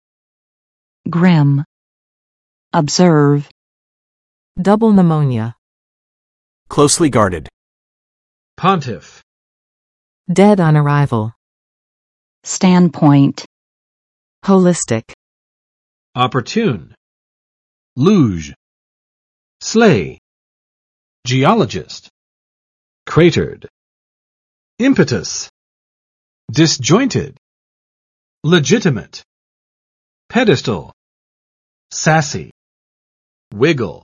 [grɪm] adj.